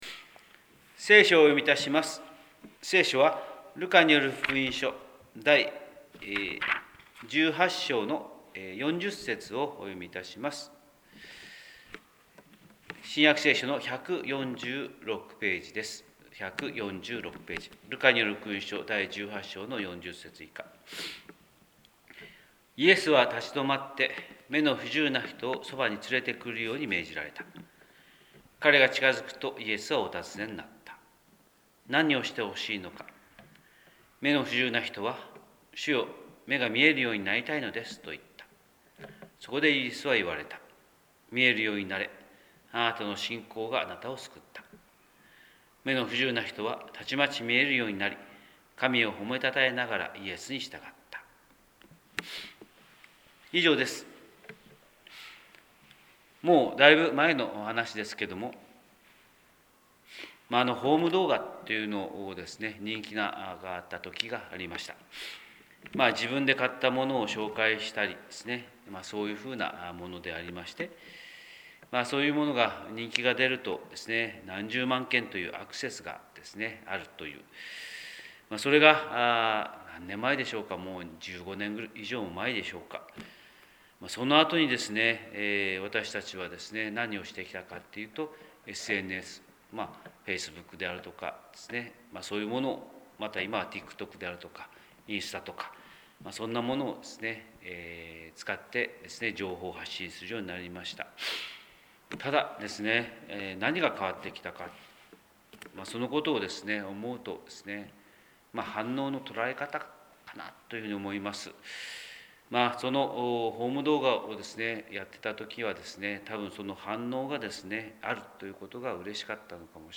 神様の色鉛筆（音声説教）: 広島教会朝礼拝241107
広島教会朝礼拝241107「反応」